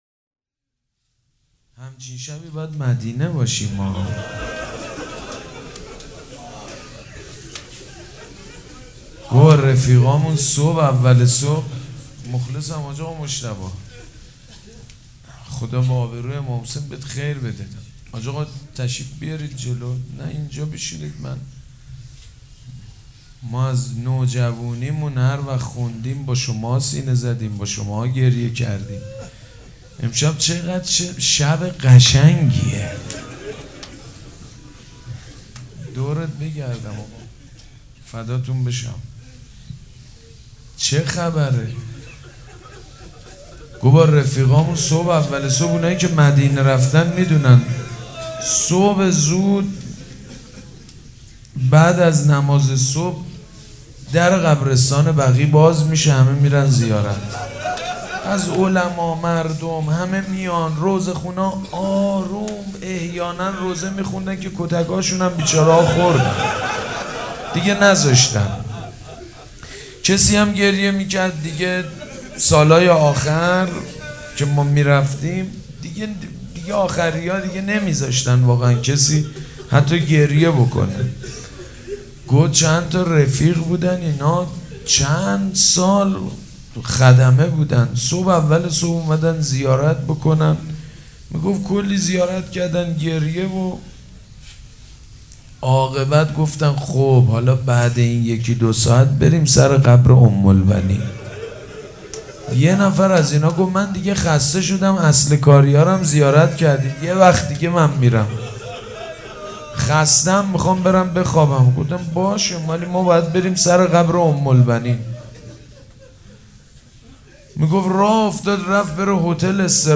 روضه بخش اول
وفات حضرت ام البنین(س) 1396
روضه